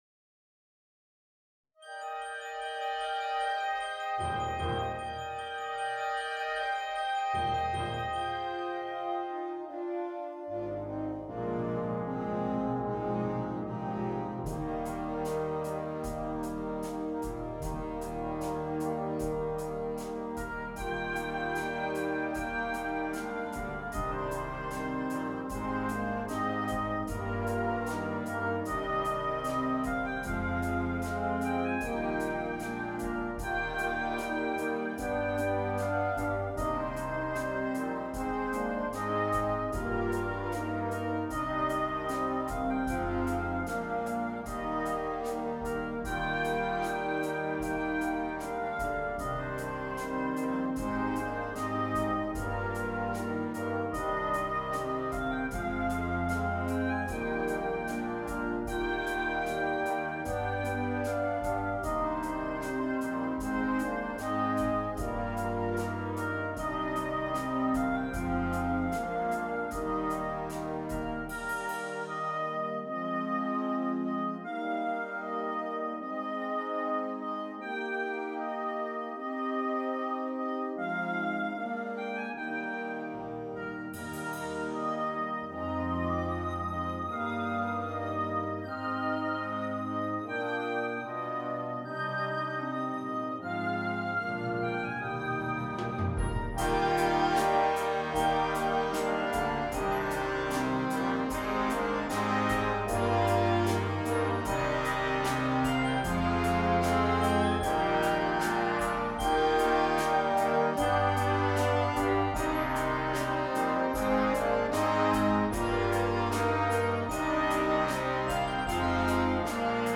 for Wind Band
A beautiful traditional melody
Oboe or Cor Anglais
Instrumentation: Wind Band